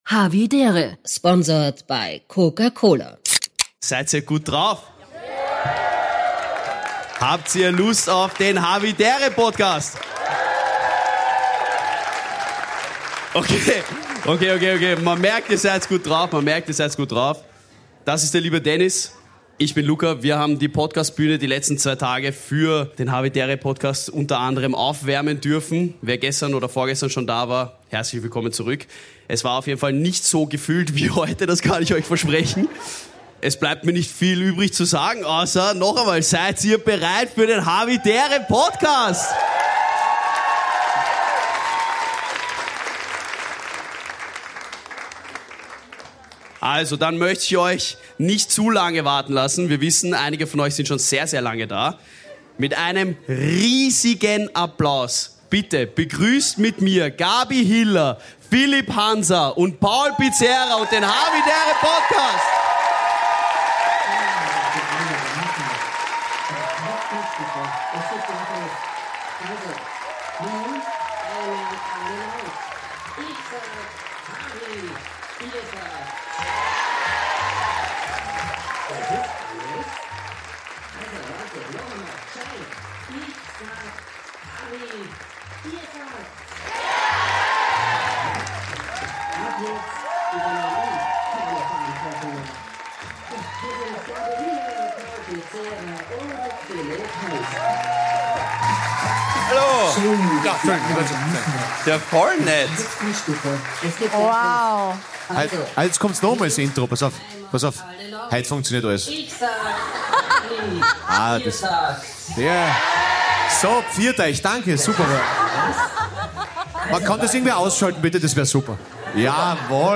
#279 Hawi D'Ehre LIVE - Donauinselfest